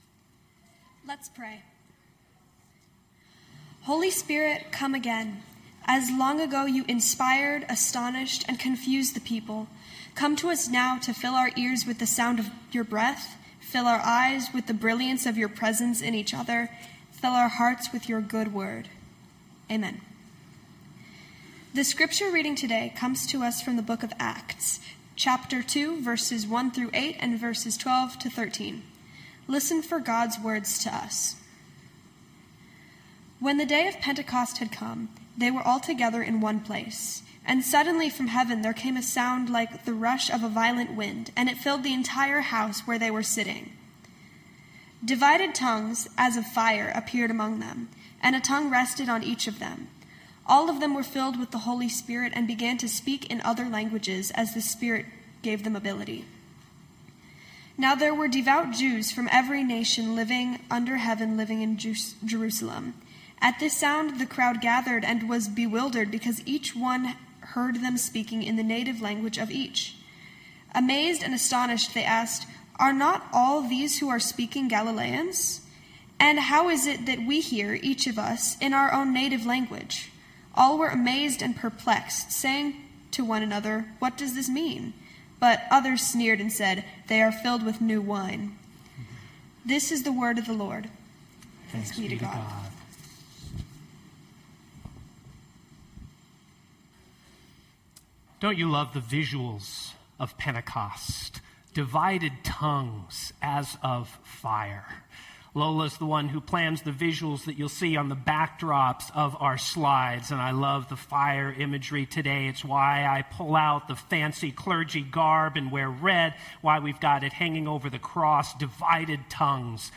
Knox Pasadena Sermons From Heaven There Came a Sound Jun 08 2025 | 00:25:00 1x 00:00 / 00:25:00 Subscribe Share Spotify RSS Feed Share Link Embed